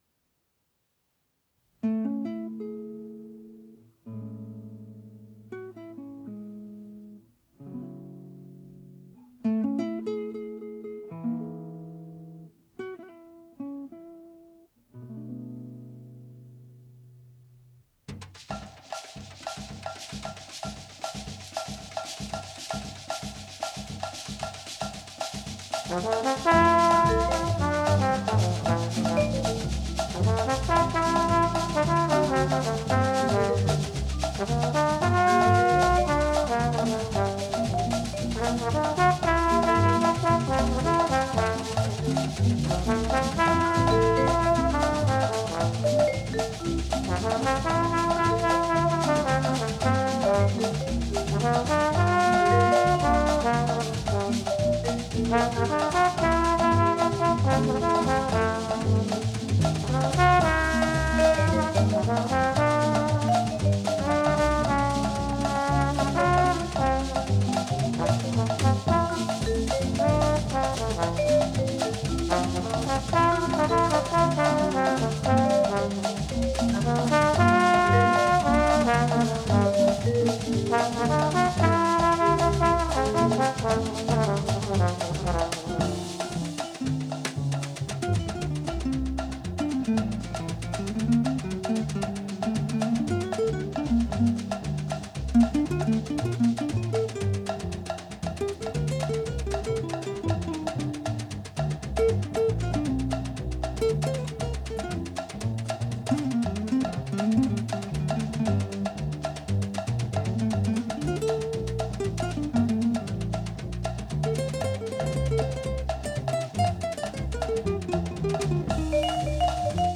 valve trombone